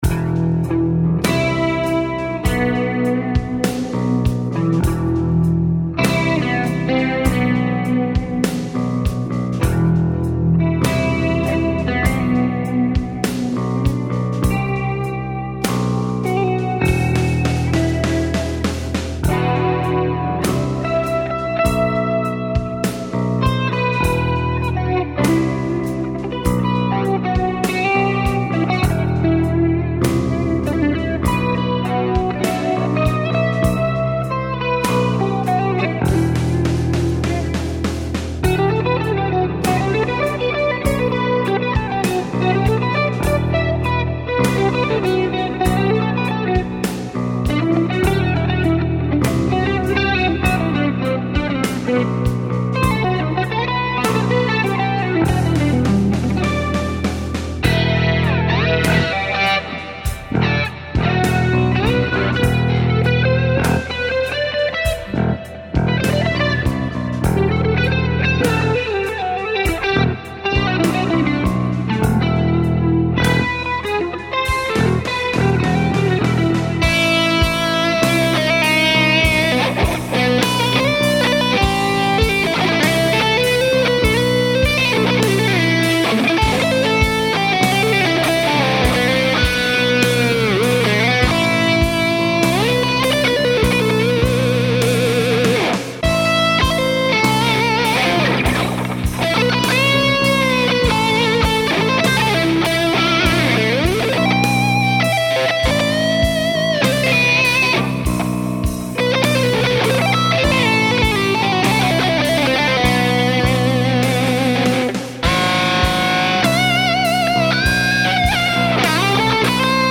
I’m a guitar player and play electric guitar and bass.
Back in 2008 I had a music revival and made some recordings of music at home with the use of my computer.